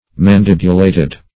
Search Result for " mandibulated" : The Collaborative International Dictionary of English v.0.48: Mandibulate \Man*dib"u*late\, Mandibulated \Man*dib"u*la`ted\, a. (Zool.)